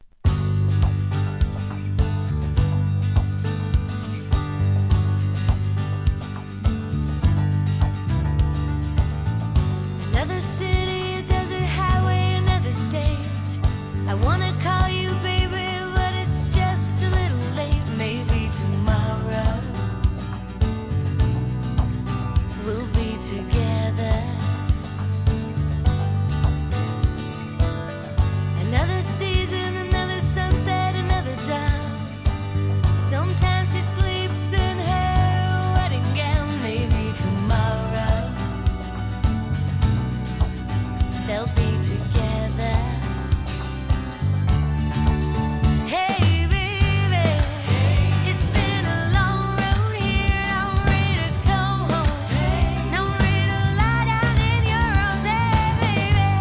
HIP HOP/R&B
しっとりとした雰囲気で穏やかな歌声に心が癒されます!